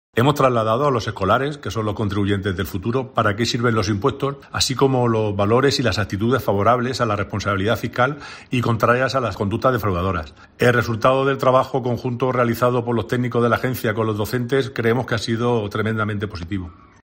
Juan Marín, director general de la Agencia Tributaria de la Región de Murcia